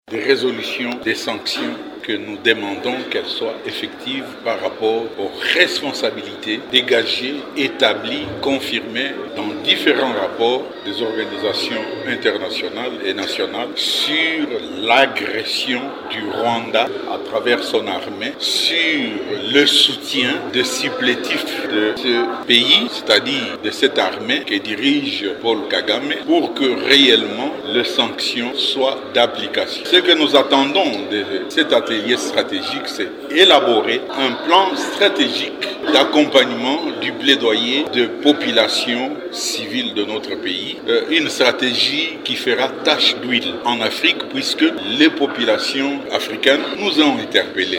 Vous pouvez écouter Paul Nsapu, président de la CNDH dans cet extrait :